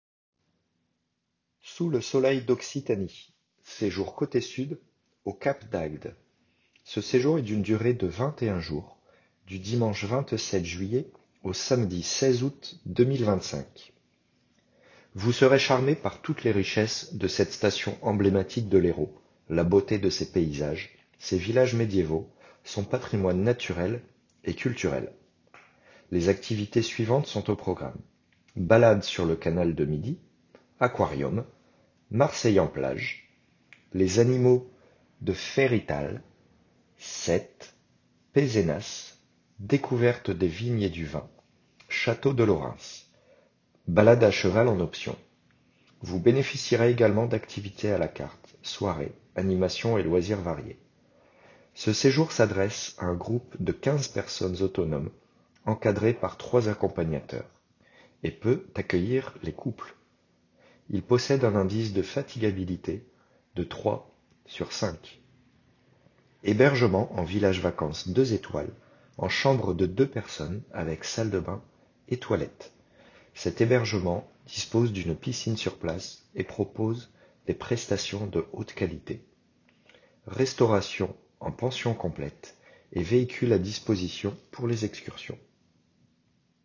Présentation audio du séjour